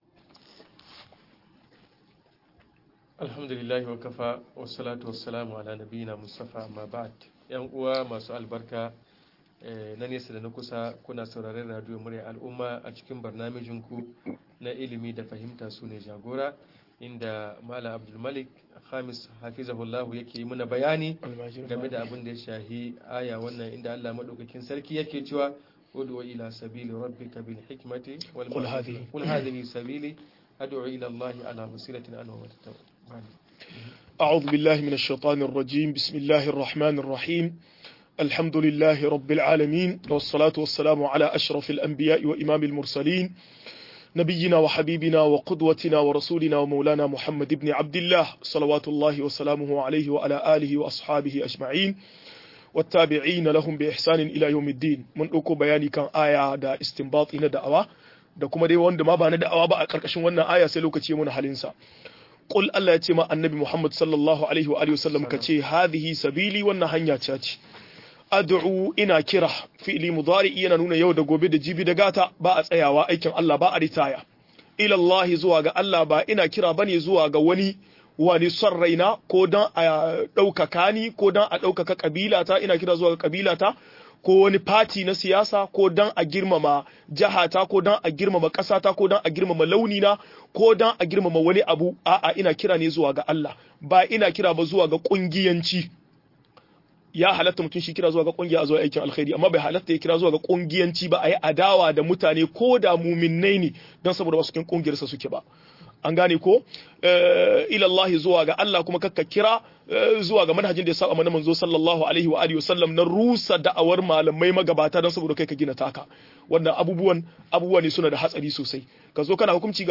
Yadda ake haɗa maudu'ai - MUHADARA